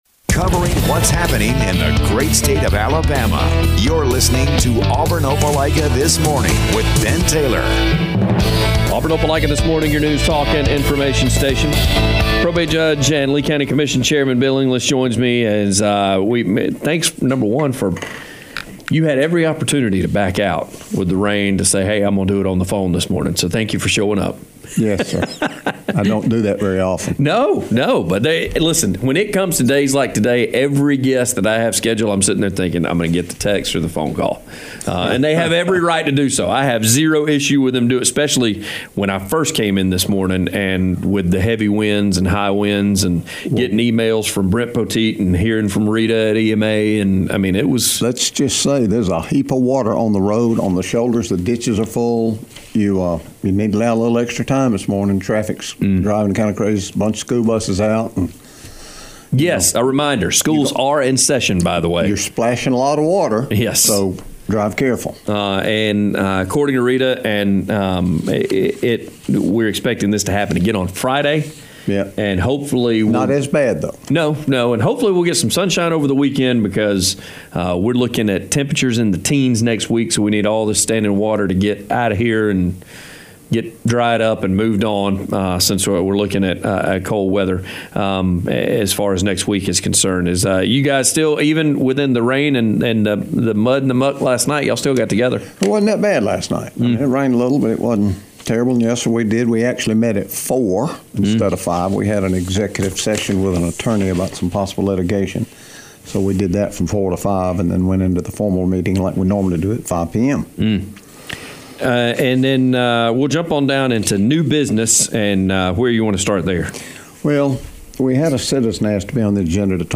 Probate Judge and Lee County Commission Chairman, Bill English recaps the first meeting of the month for the Lee County Commission...